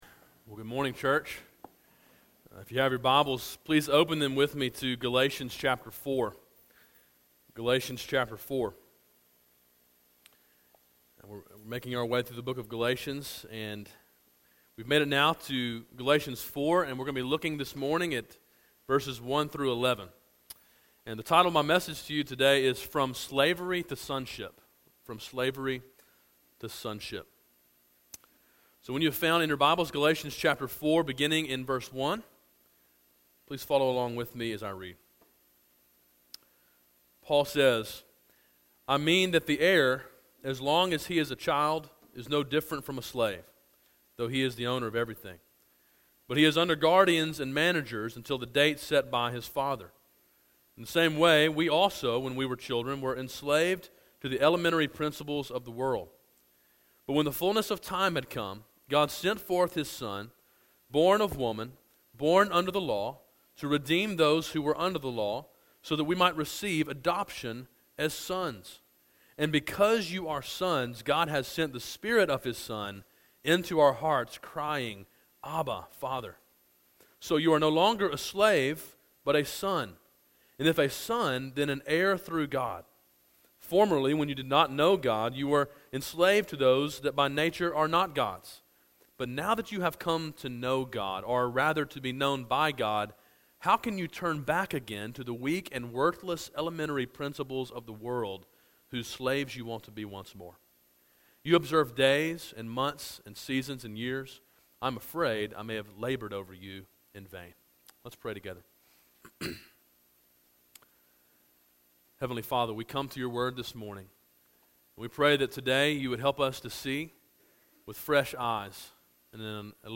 A sermon in a series titled Freedom: A Study of Galatians.